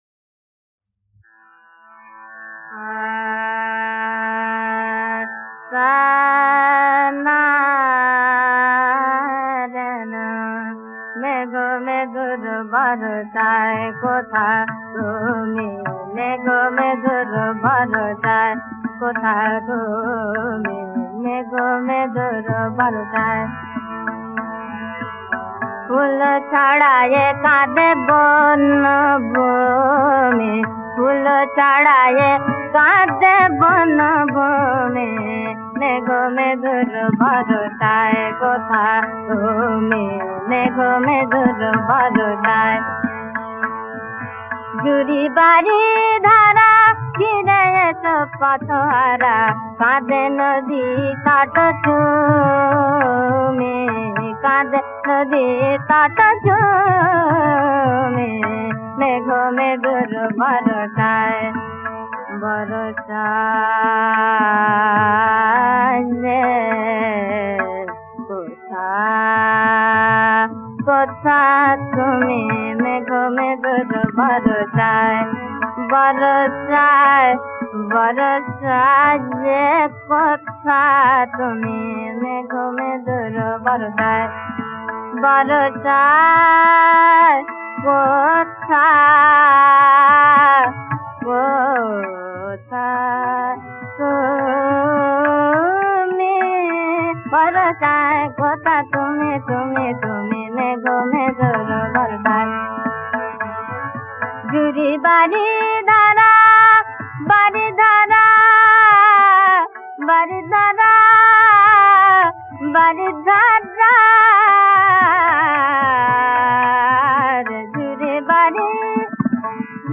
• সুরাঙ্গ: খেয়ালাঙ্গ
খেয়াল ভাঙা গান।
• রাগ: জয়জয়ন্তী
• তাল: ত্রিতাল